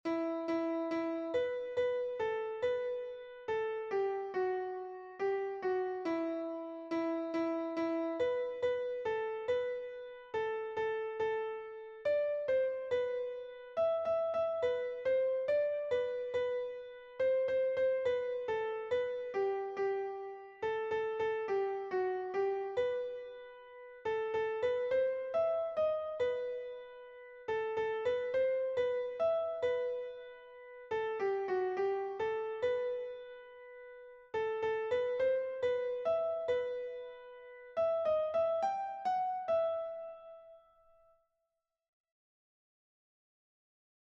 Hamalauko handia (hg) / Zazpi puntuko handia (ip)
10 8A 10 8A 10 8A 10 8A 10 8A 10 8A 10 8A (hg) / 18A 18A 18A 18A 18A 18A 18A (ip)